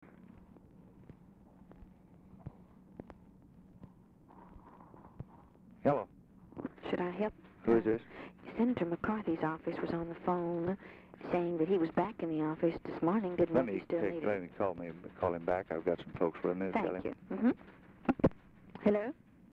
Telephone conversation # 1871, sound recording, LBJ and OFFICE SECRETARY, 2/4/1964, time unknown | Discover LBJ
Format Dictation belt
Location Of Speaker 1 Oval Office or unknown location